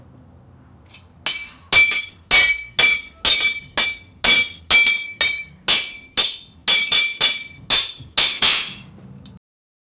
Warm, natural, and deeply calming. 0:33 Não! Tambores tocando e espadas sendo pegas no arsenal 0:10 epic battle horn sound to signal an incoming wave.
no-tambores-tocando-e-esp-qntjsdwt.wav